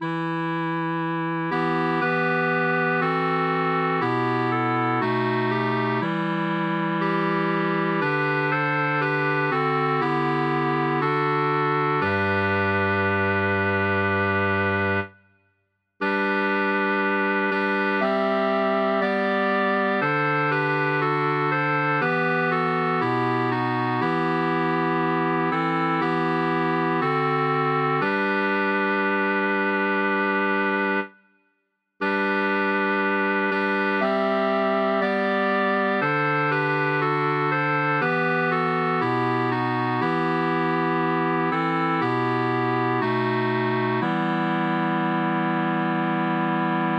Îndepărtându-se de psaltică, a reuşit să compună o populară melodie plăcută, pe alocuri melancolică, uneori săltăreaţă, la înfloriturile vocilor înalte.
cor mixt